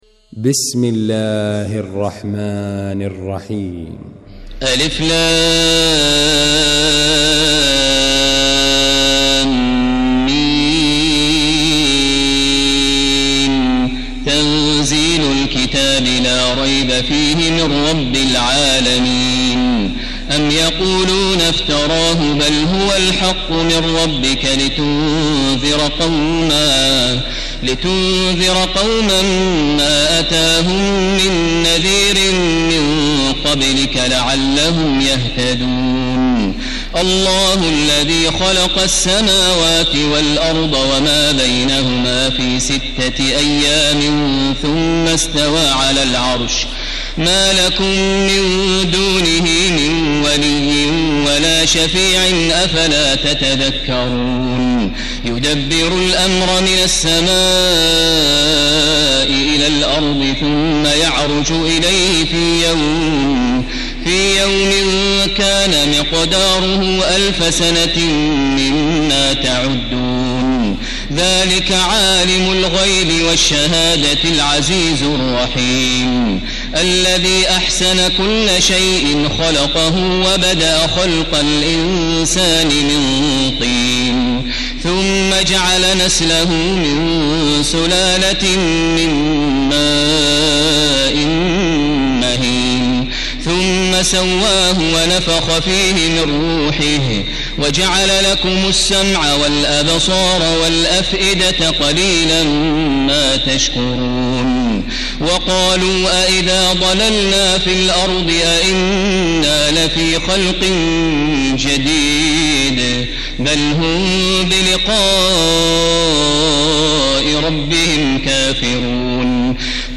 المكان: المسجد الحرام الشيخ: فضيلة الشيخ ماهر المعيقلي فضيلة الشيخ ماهر المعيقلي السجدة